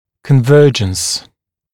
[kən’vɜːʤəns][кэн’вё:джэнс]сближение, плотность прилегания (основания брекета к поверхности зуба)